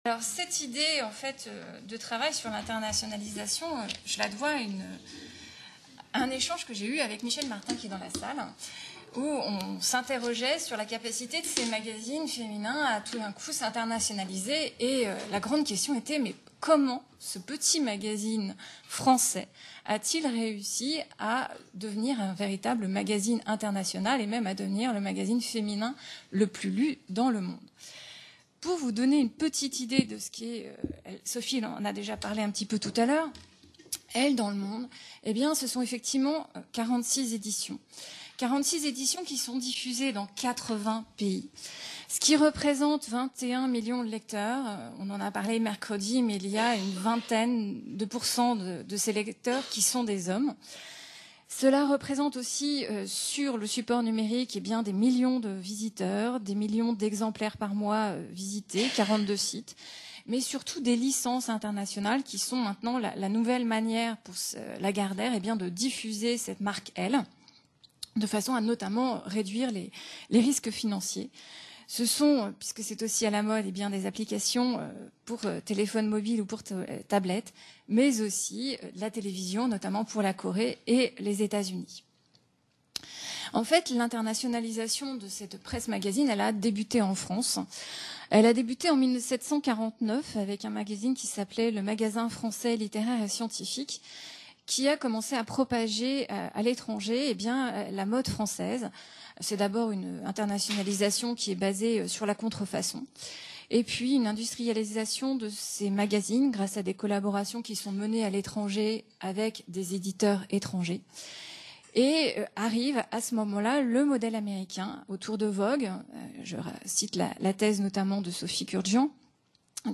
Colloque "Elle fête ses 70 ans"